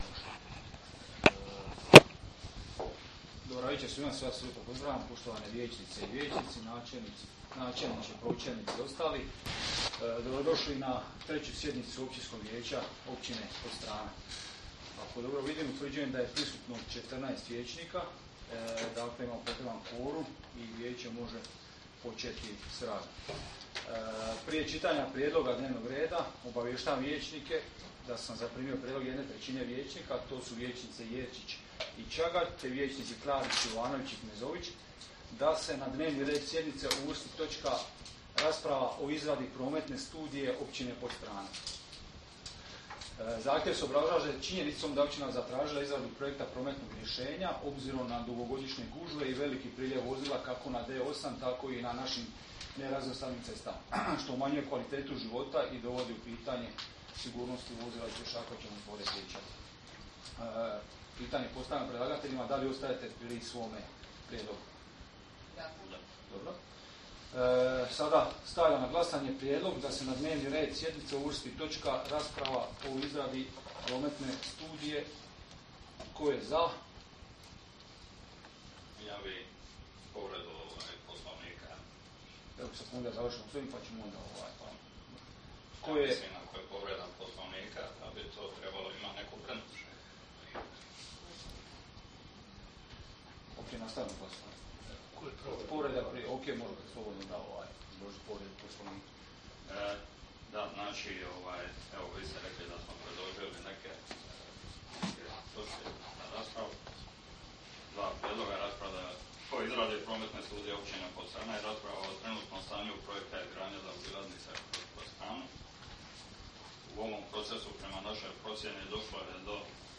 Sjednica će se održati dana 10. rujna (srijeda) 2024. godine u 19,00 sati u Vijećnici Općine Podstrana.